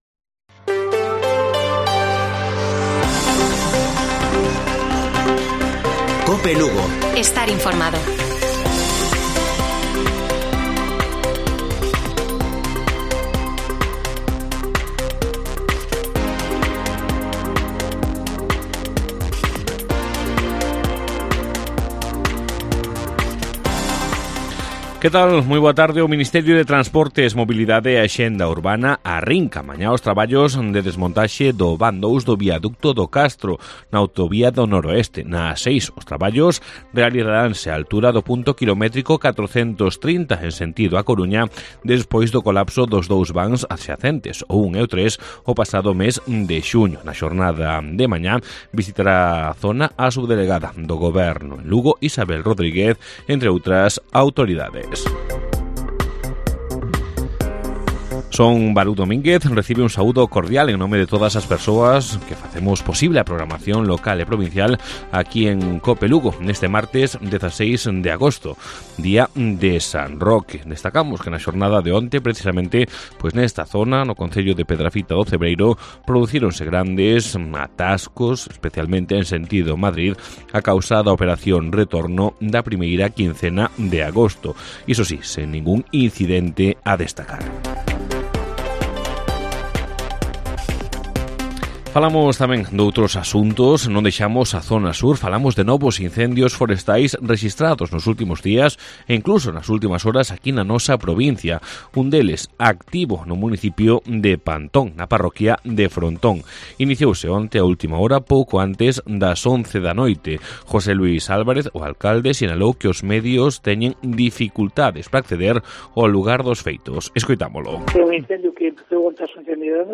Informativo Mediodía de Cope Lugo. 16 DE AGOSTO. 14:20 horas